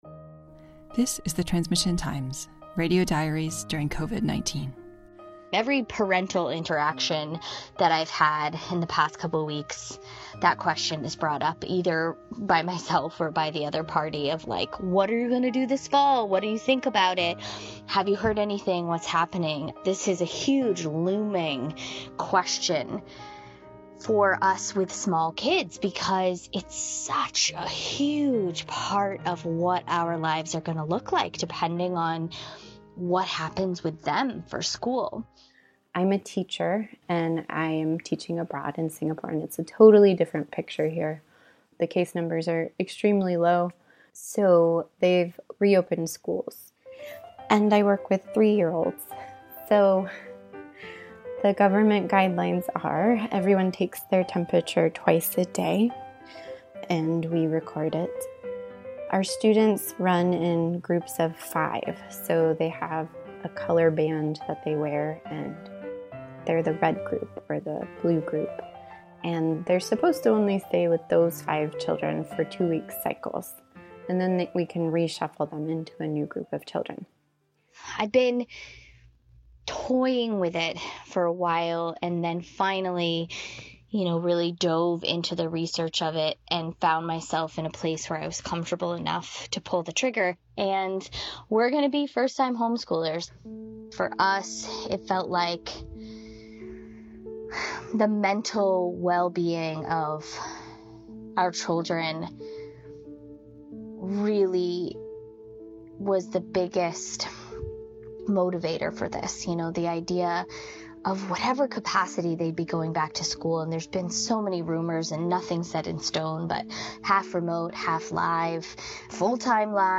Audio diaries from around the world give us a glimpse of daily life during the pandemic. In these non-narrated, sound-rich snapshots of life we hear the joys and celebrations, the challenges and desperations, of mothers and fathers, sons and daughters, old and young.